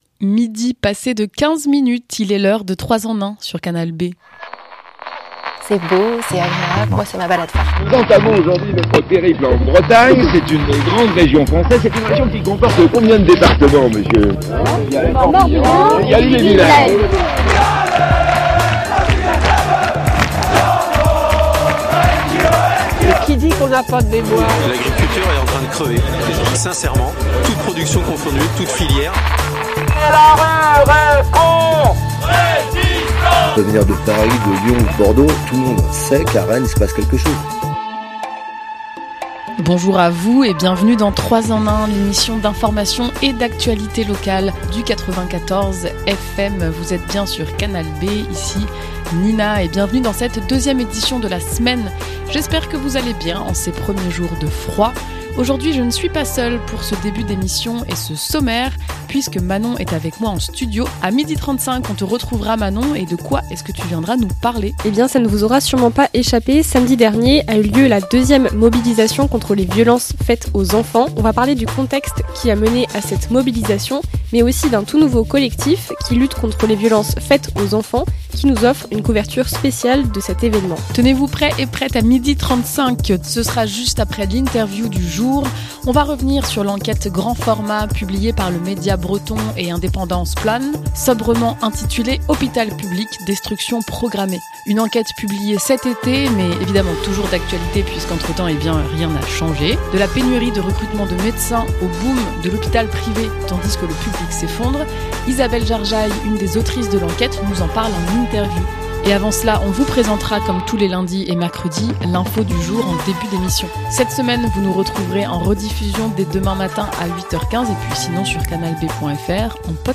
L'interview
Un jeune collectif de défense des droits des enfants nous emmène dans la mobilisation du samedi 15 novembre à Rennes. Une couverture exclusive de cette deuxième manifestation qui met en lumière la lutte contre les violences faites aux enfants.